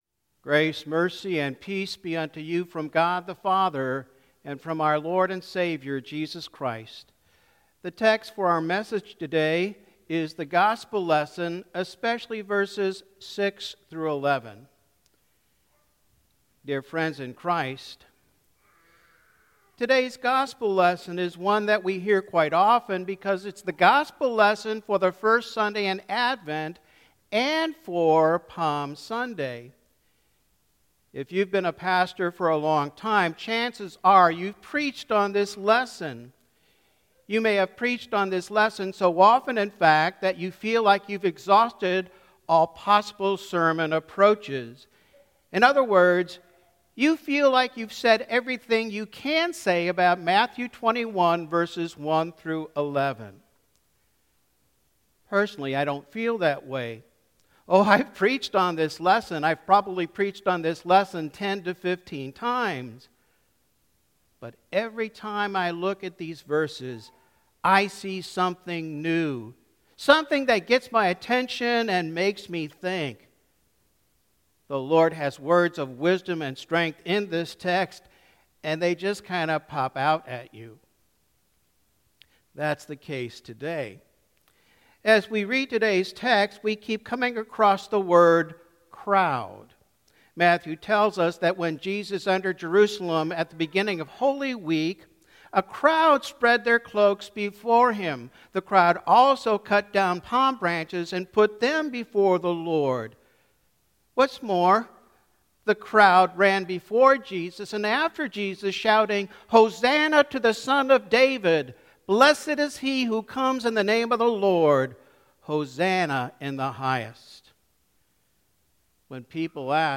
Advent